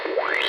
radiobutton_checked.wav